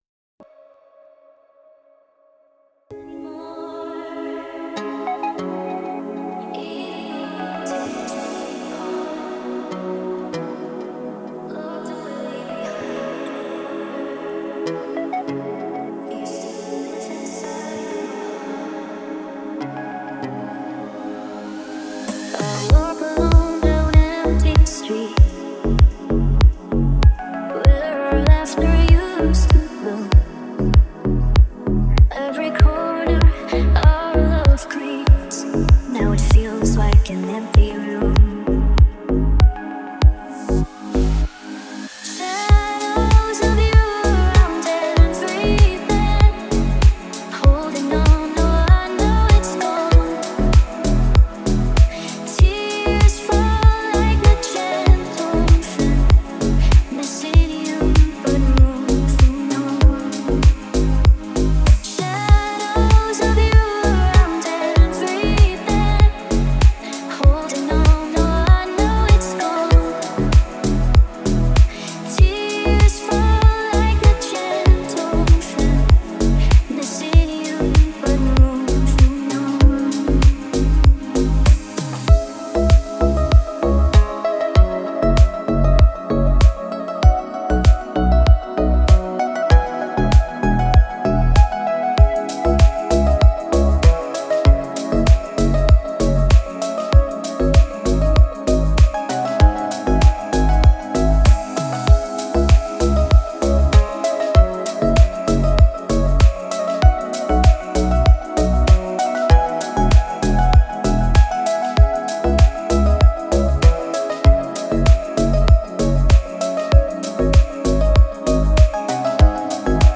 Категория: Спокойная музыка
спокойная музыка